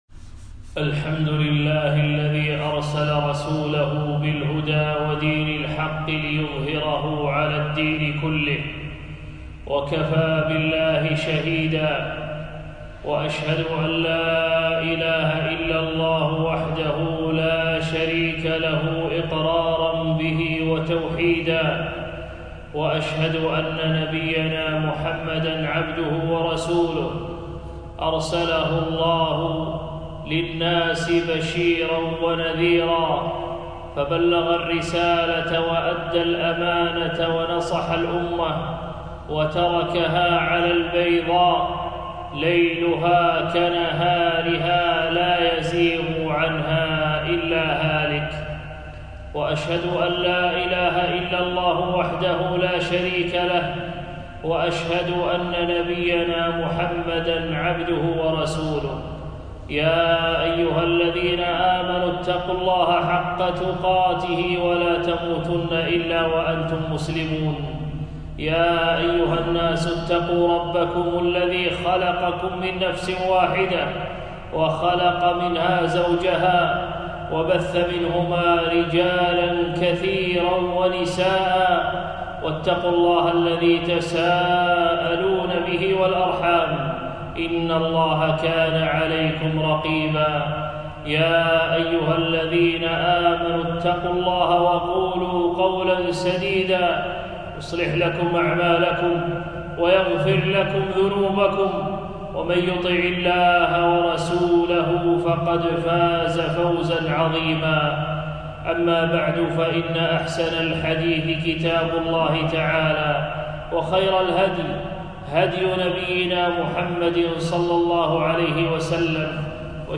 خطبة - الأمر بلزوم السنة والحذر من البدعة